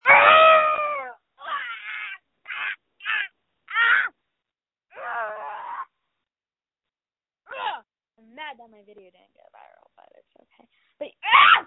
• When you call, we record you making sounds. Hopefully screaming.